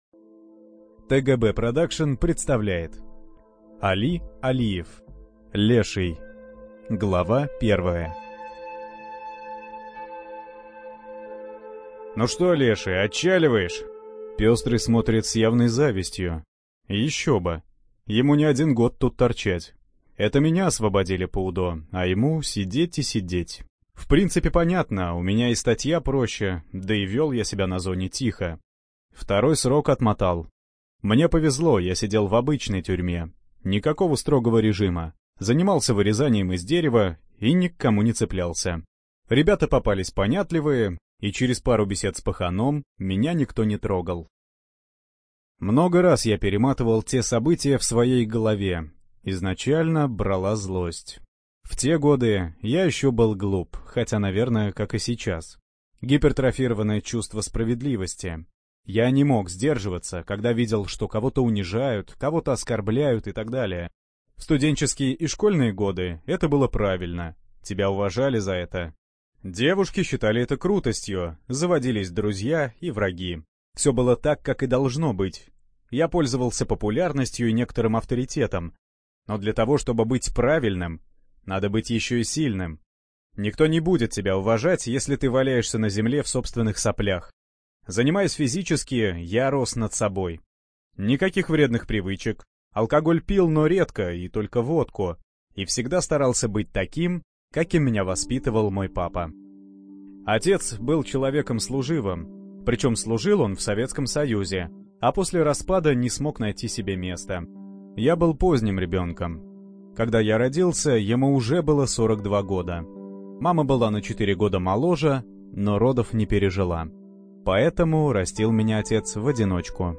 Информация о книге Леший (Библиотека ЛОГОС)